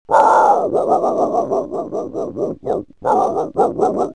Hungry Dog Sound Button - Free Download & Play